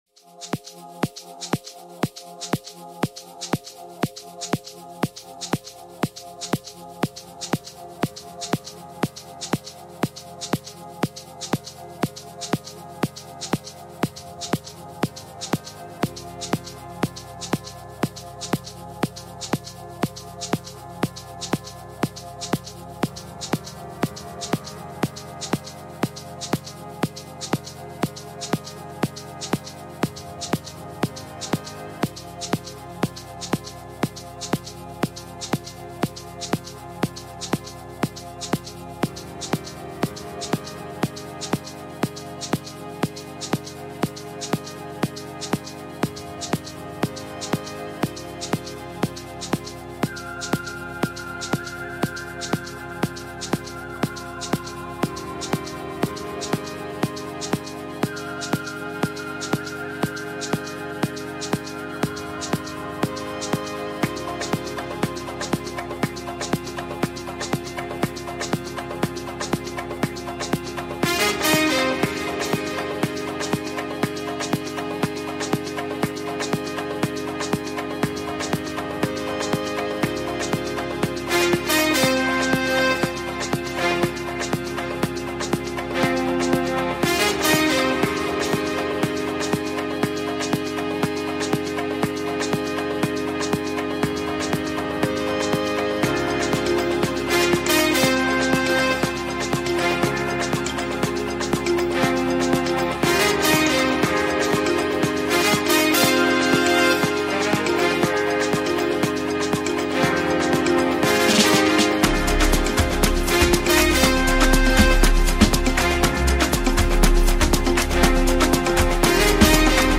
returns with a soulful record titled